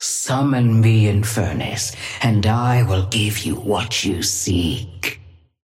Sapphire Flame voice line - Summon me, Infernus, and I will give you what you seek.
Patron_female_ally_inferno_start_04.mp3